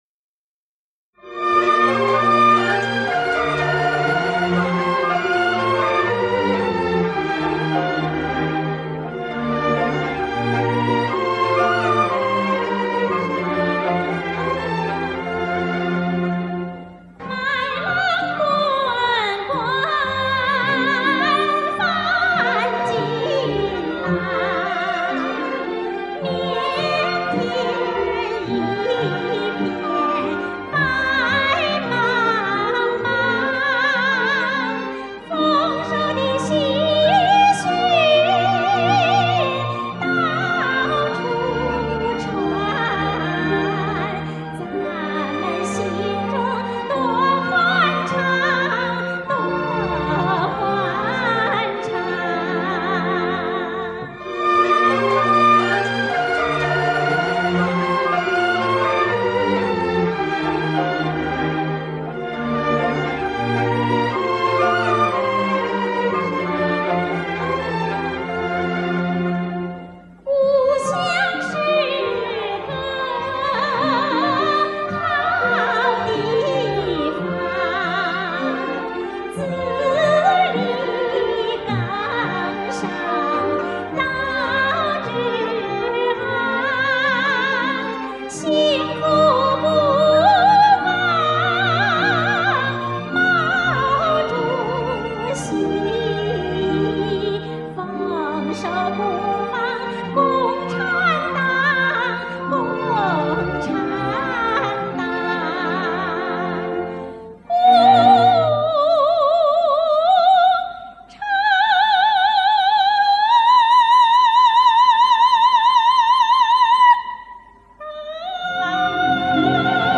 超长极限版本，弥足珍贵，原声电影歌曲大碟，顶尖音频技术处理中国电影博物馆馆藏珍品，史料详实，图文丰富，权威，鲜为人知.